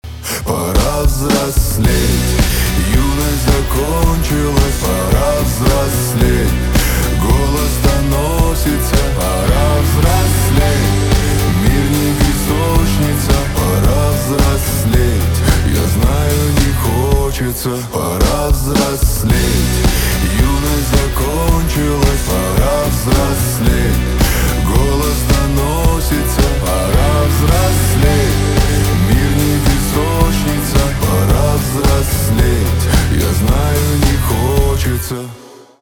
русский рок , грустные
гитара , барабаны